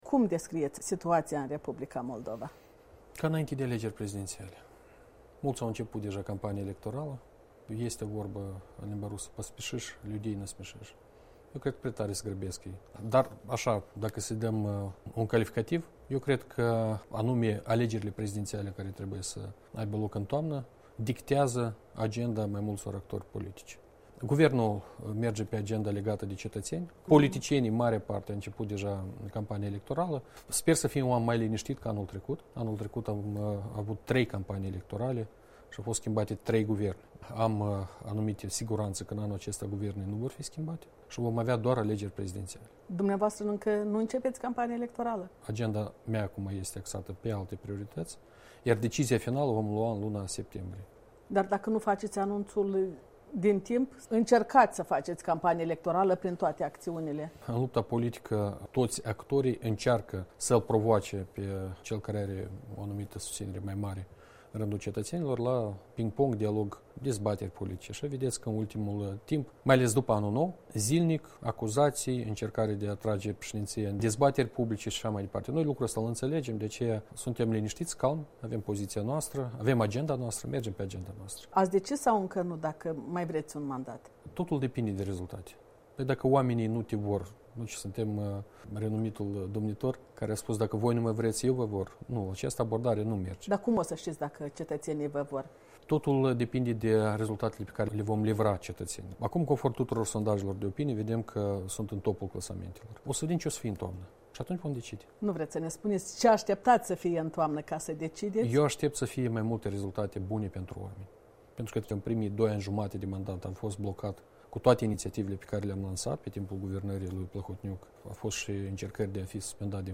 Un interviu cu președintele Igor Dodon